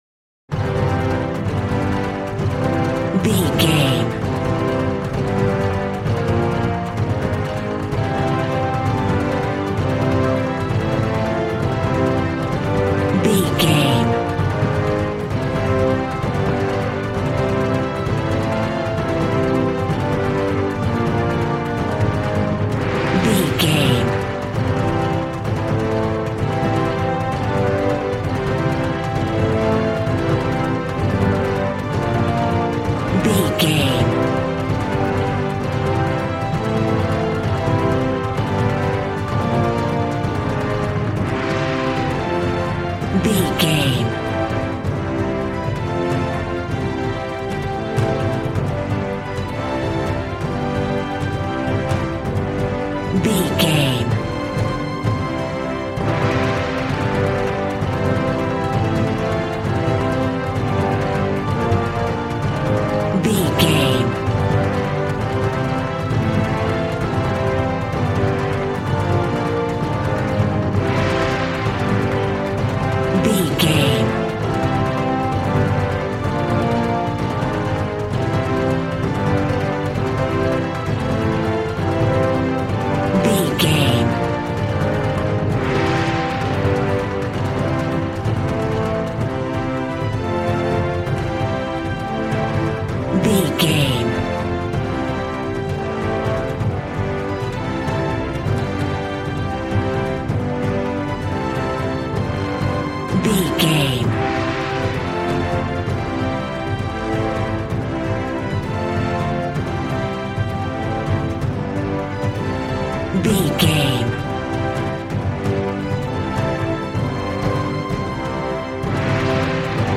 Action and Fantasy music for an epic dramatic world!
Ionian/Major
groovy
drums
bass guitar
electric guitar